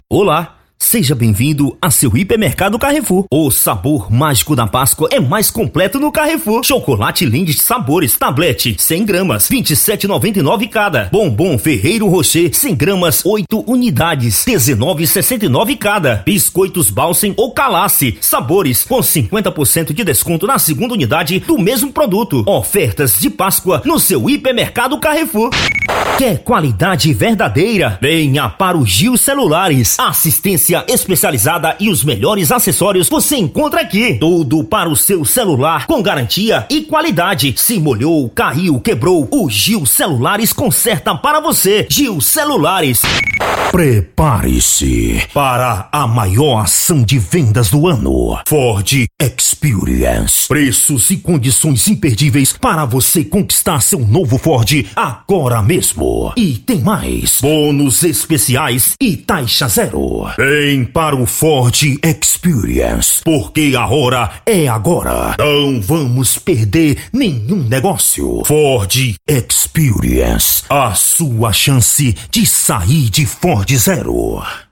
Varejo: